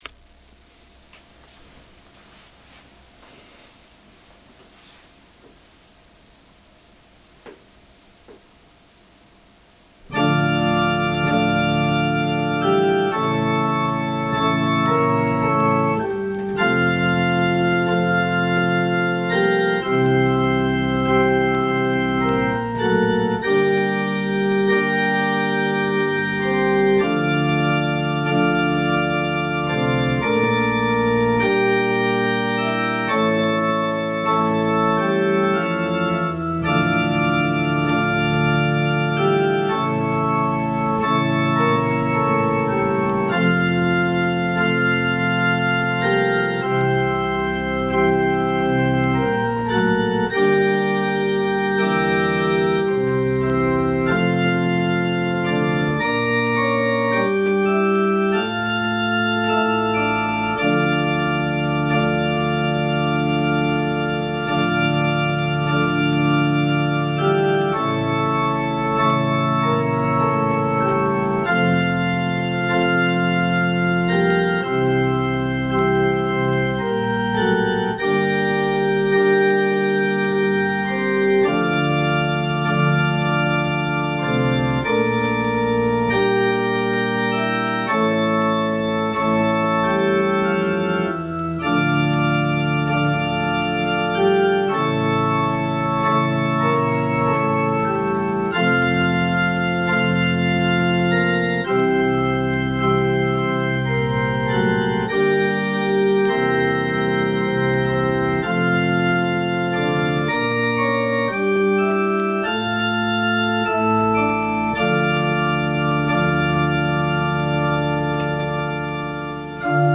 ここではあえて、ピアノで奏でる、ハープシコード組曲第２番より第４曲、サラバンドをおすすめします。
ものすごくシンプルながら、重厚感あふれるずっしりした和音。ゆったり２拍分を基調とする３拍子。
そして後半部は左手の４分音符を聴かせる、動きがありつつもクライマックスに盛り上げる音運び。